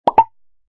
UI Pop-Up Alert Sound: Short Notification Tone
Description: UI pop-up alert sound effect – Short Notification Tone. Pop up window sound.
UI-pop-up-alert-sound-effect.mp3